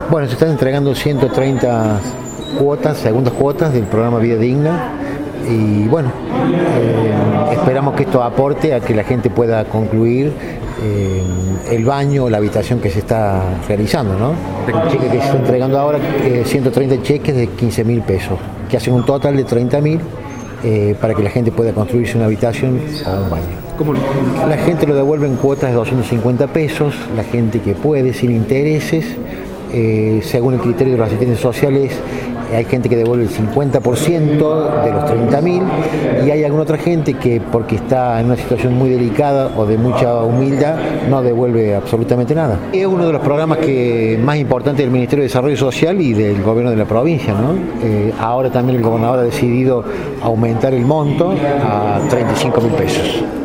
El acto tuvo lugar en el Centro Cívico de la ciudad de Río Cuarto.